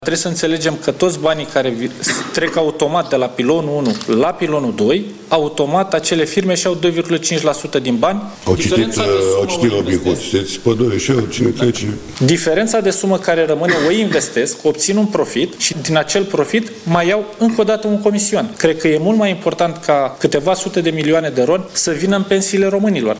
Ministrul Finanțelor explica atunci, tot într-un interviu la Antena 3, că intenționează să elimine comisioanele pe care și le iau administratorii fondurilor, iar prim-ministrul își arăta susținerea.
24aug-13-Ionut-Misa-voce-verificat.mp3